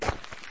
footfall.ogg